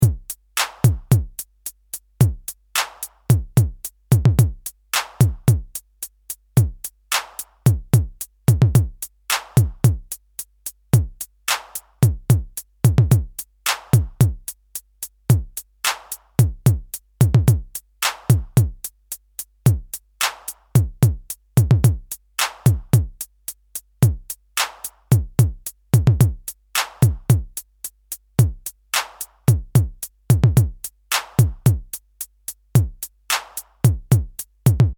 Bucle de percusión electrónica
Música electrónica
percusión
repetitivo
rítmico
sintetizador